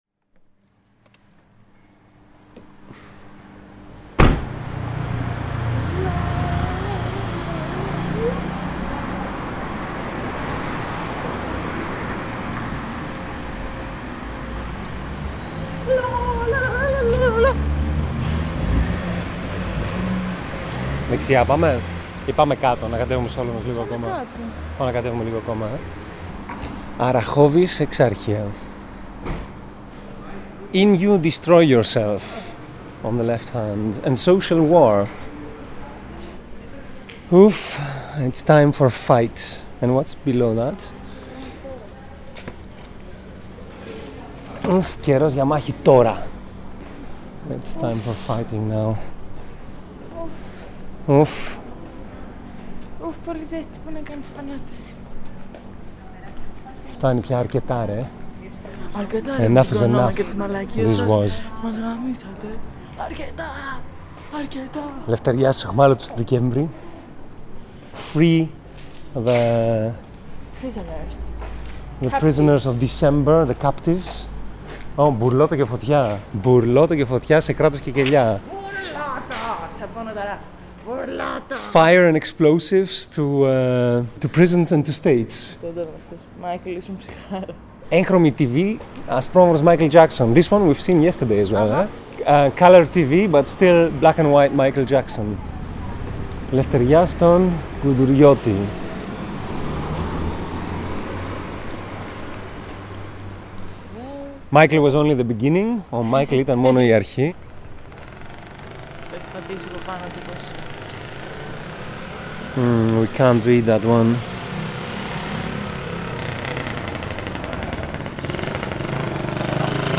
What emerged is an audio deríve , that transports our mood as much as it records the existing graffiti, while weaving our play into a nocturnal web of urban sound.